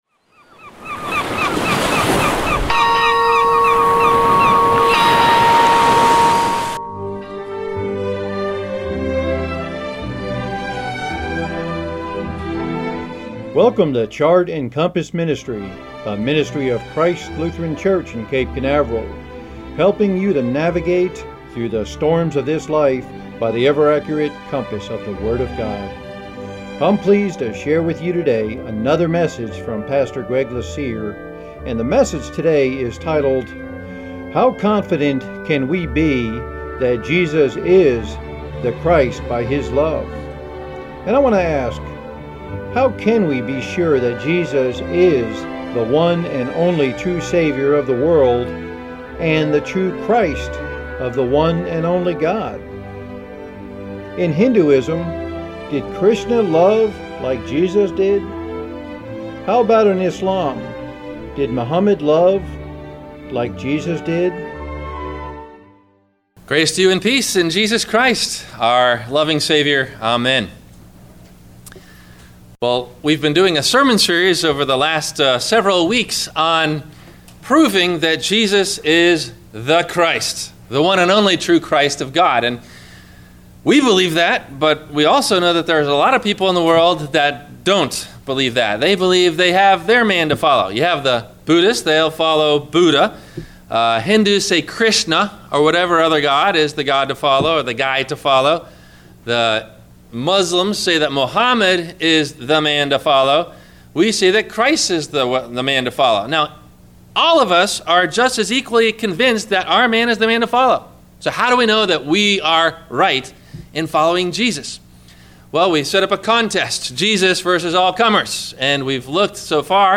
How Confident can we be that Jesus is the Christ by His Love? – WMIE Radio Sermon – January 18 2016